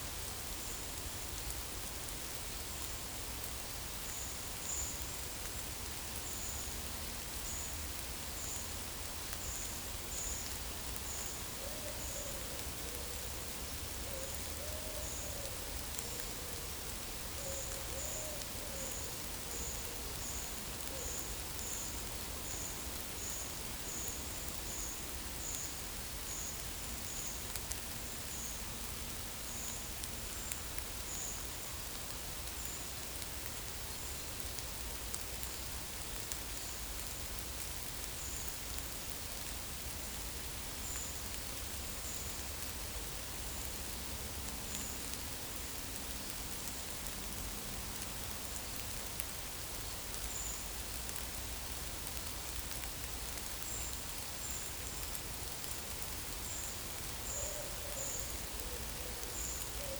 Monitor PAM - Renecofor
Certhia brachydactyla
Certhia familiaris
Turdus iliacus
Aegithalos caudatus
Streptopelia decaocto
Columba palumbus